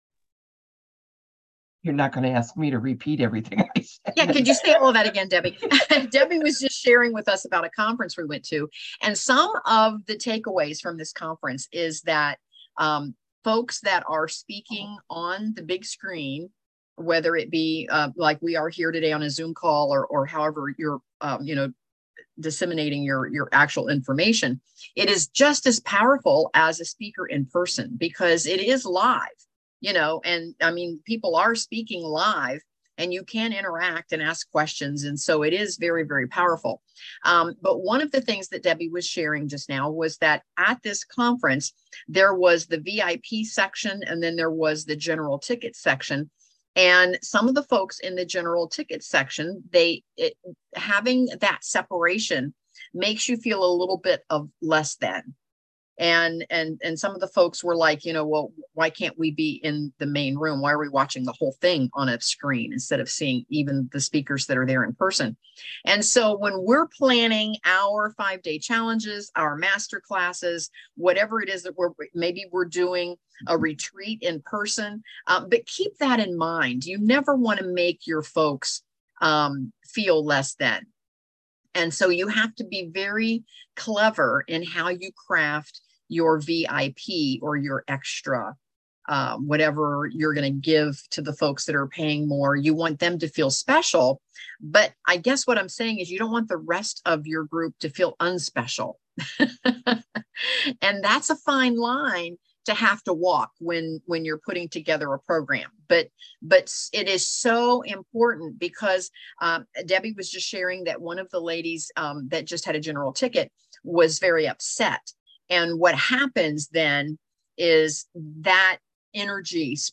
Join in our mastermind group and listen as we discuss how to launch our very own Mastermind/Masterclass/Challenge. Then where do we post the REPLAY for our own audience?